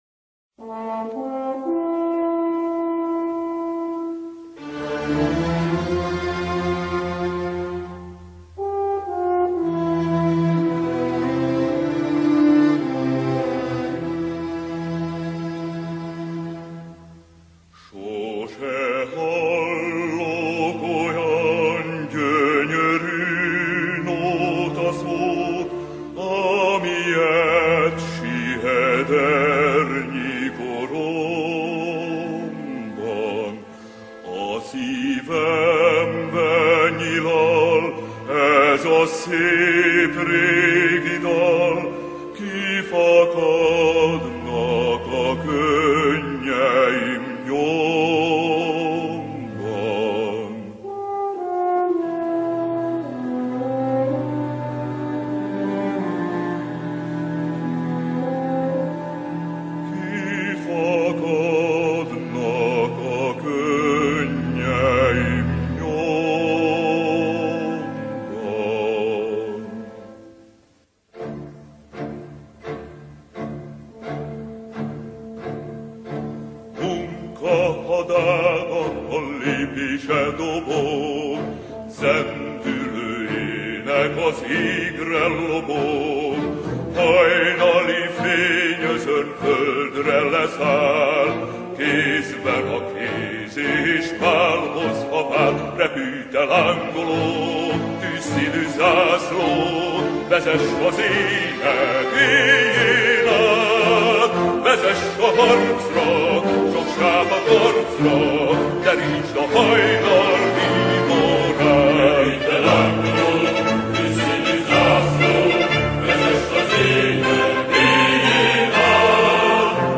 Попурри на венгерском языке из песен